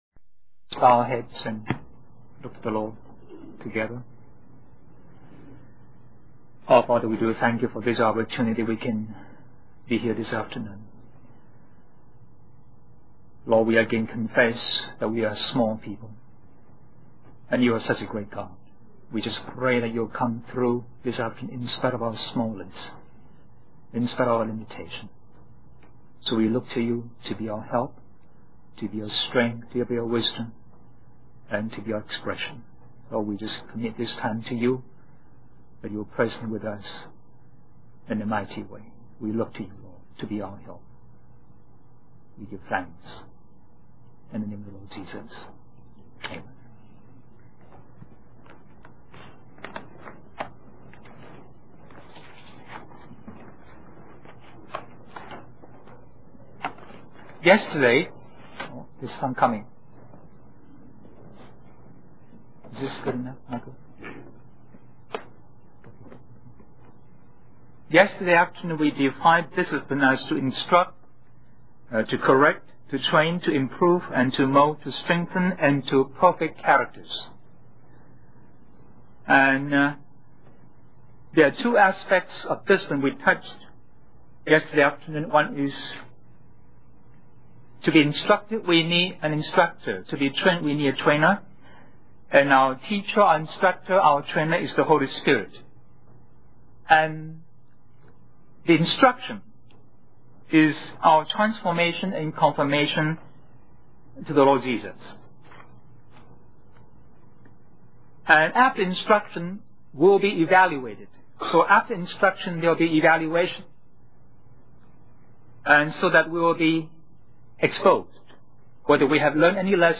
Christian Family Conference
Question and Answer